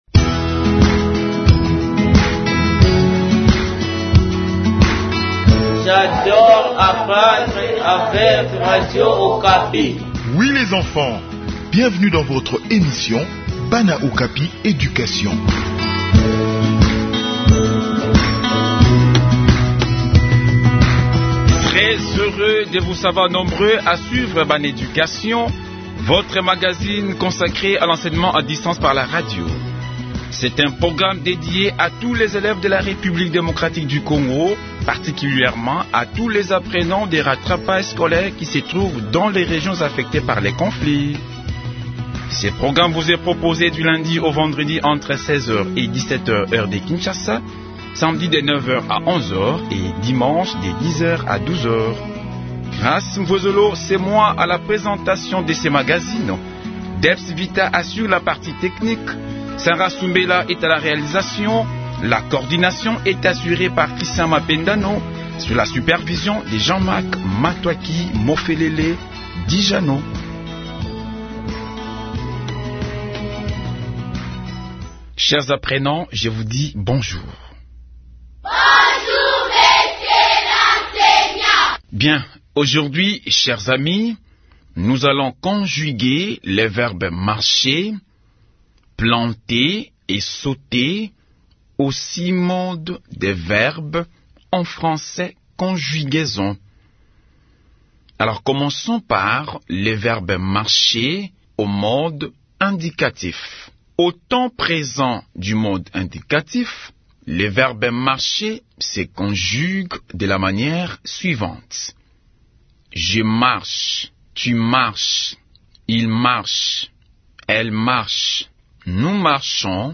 Enseignement à distance: conjugaison de verbes marcher, planter et sauter